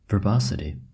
Ääntäminen
US : IPA : /vɚˈbɑsəti/